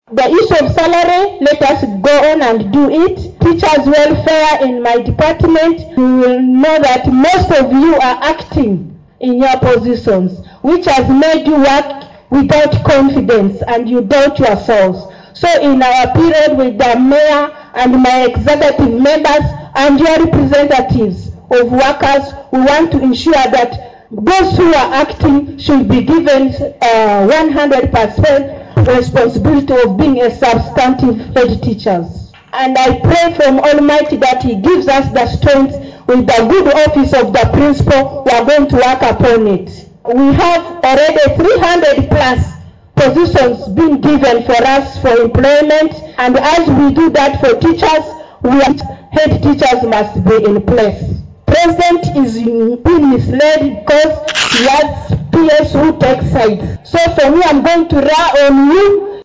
Madam Fadumala Speaking